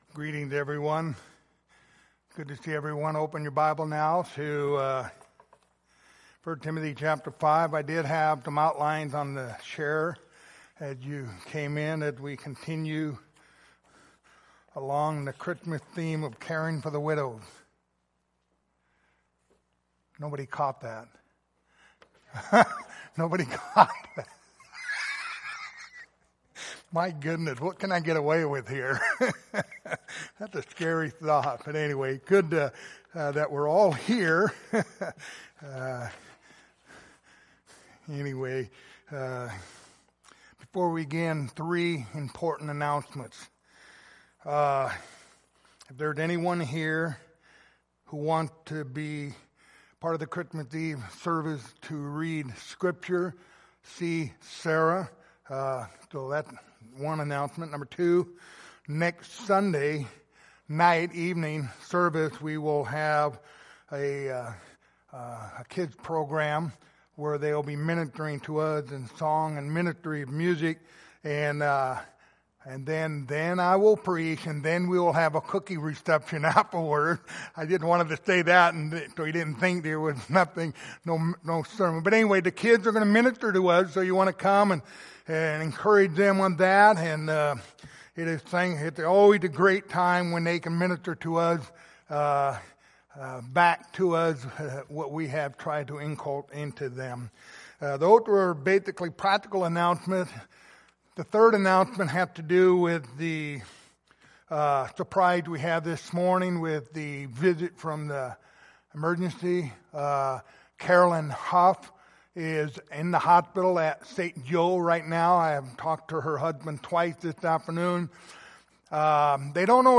Passage: 1 Timothy 5:9-16 Service Type: Sunday Evening